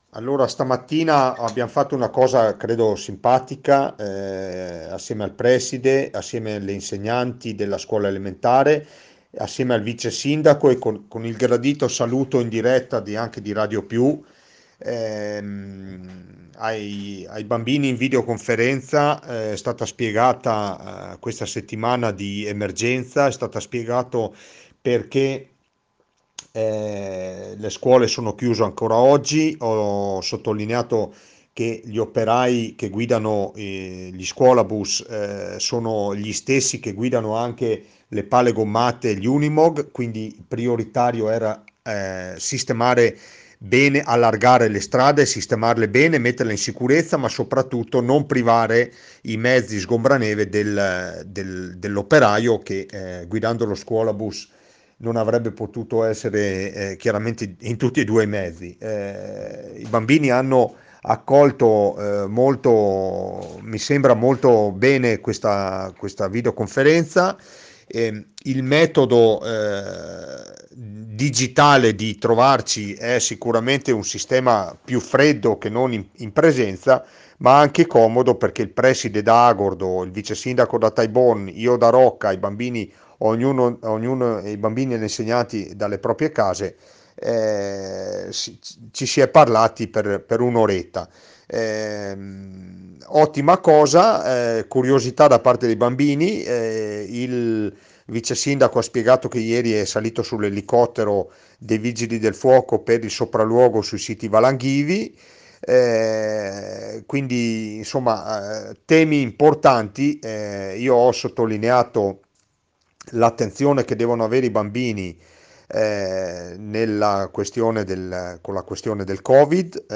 ROCCA PIETORE Nella mattinata videoconferenza tra il comune di Rocca Pietore, Radio Più e i bambini delle scuole di Rocca Pietore per spiegare le motivazioni della chiusura delle scuole in questi giorni e per parlare anche dell’emergenza Covid-19.
IL SINDACO DI ROCCA PIETORE ANDREA DE BERNARDIN
DE-BERNARDIN-VIDEOCONFERENZA-BAMBINI-SCUOLE.mp3